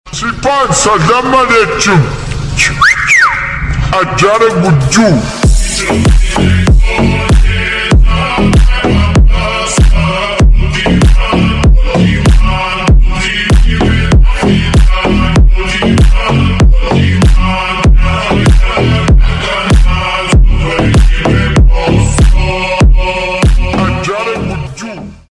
• Качество: 320, Stereo
мелодичные
Музыка приятная танцевальная